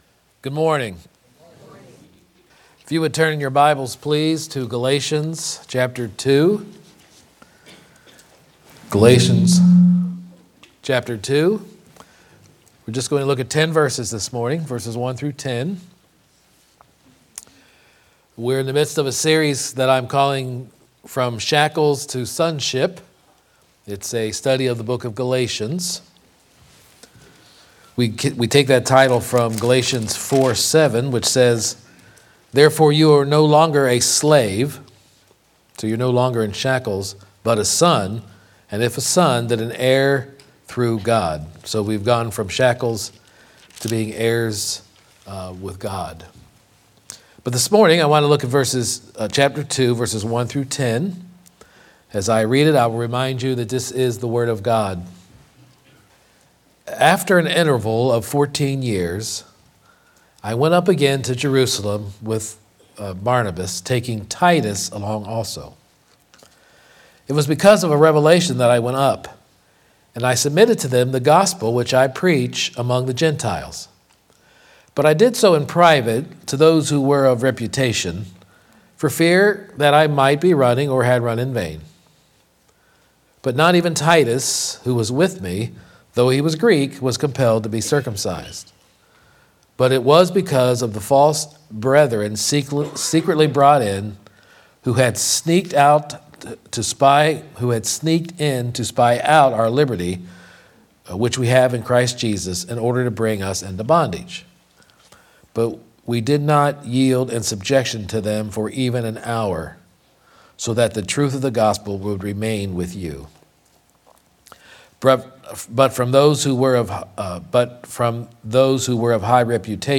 3-23-25-Sermon-Grace-Confirmed-.mp3